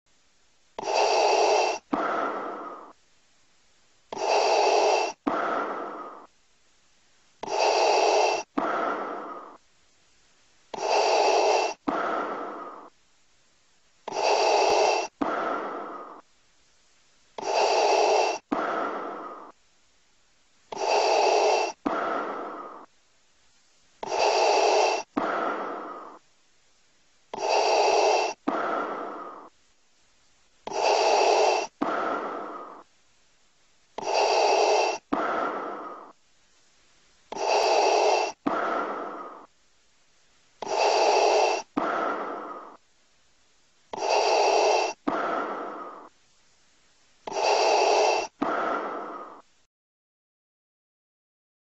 Темный звук дыхания ситха